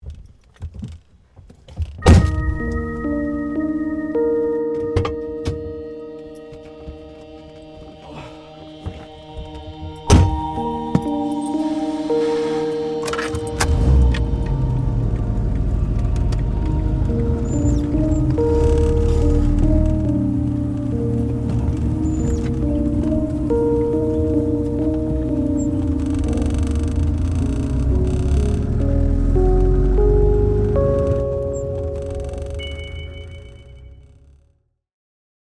Un personaje se aleja de su pasado con determinación y elegancia. Es un momento de cambio, con una mezcla de melancolía, libertad y fuerza interior. La música acompaña con energía motivadora, toques nostálgicos y un aire desafiante hacia un nuevo comienzo.